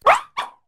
fidough_ambient.ogg